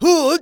xys蓄力9.wav 0:00.00 0:00.43 xys蓄力9.wav WAV · 37 KB · 單聲道 (1ch) 下载文件 本站所有音效均采用 CC0 授权 ，可免费用于商业与个人项目，无需署名。
人声采集素材